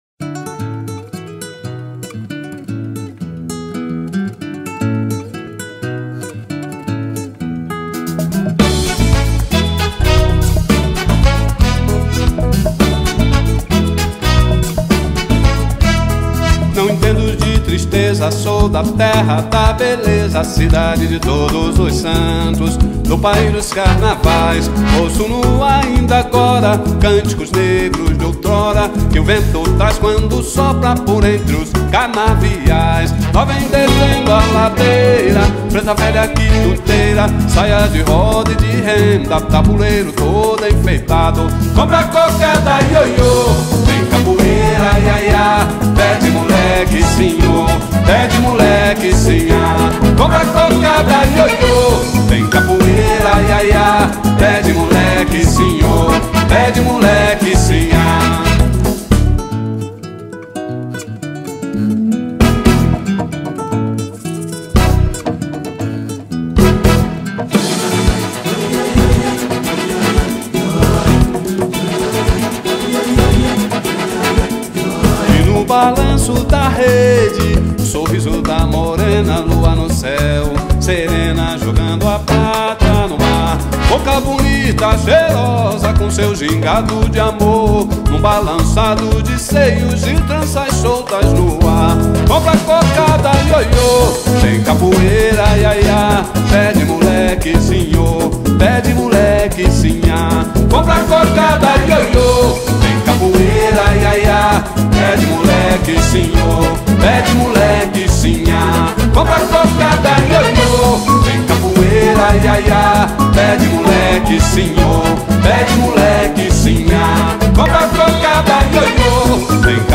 A Essência do Samba-Rock